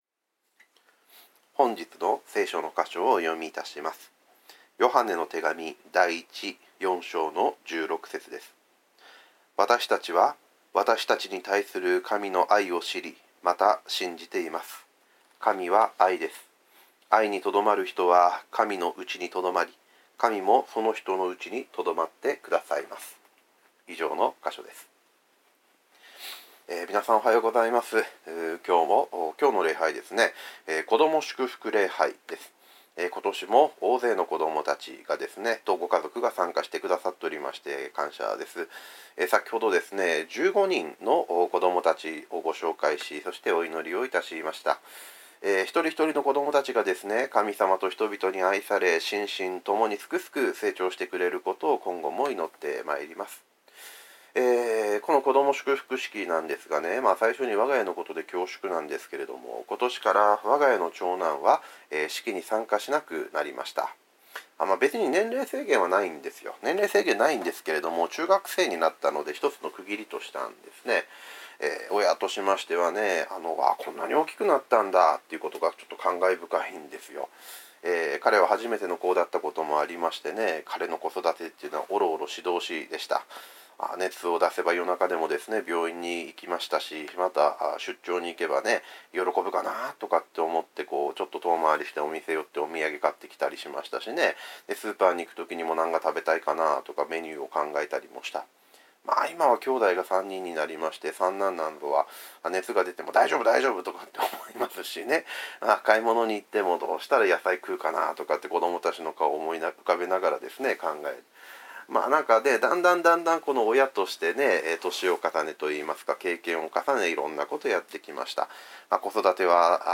主日礼拝。